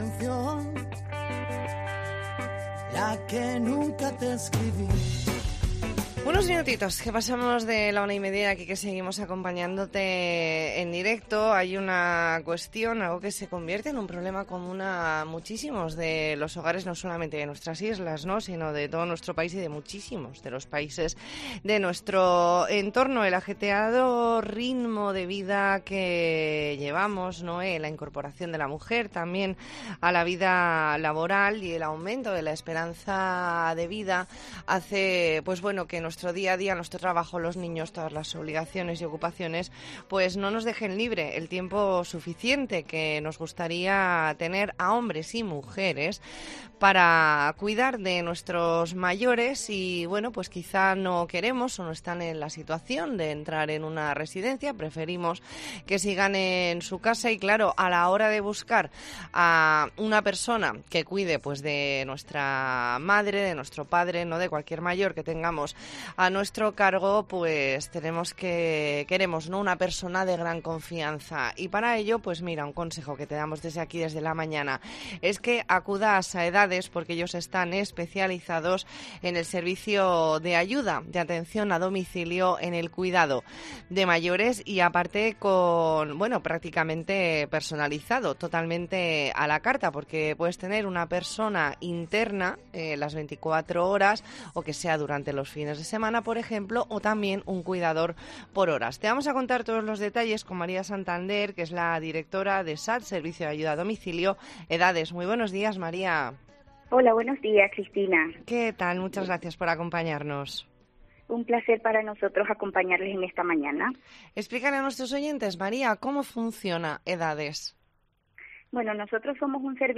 E ntrevista en La Mañana en COPE Más Mallorca, lunes 24 de octubre de 2022.